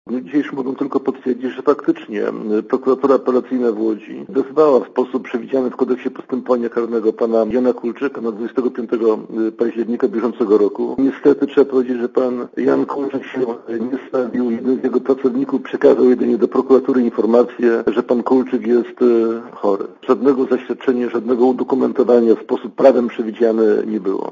Posłuchaj komentarza Kazimierza Olejnika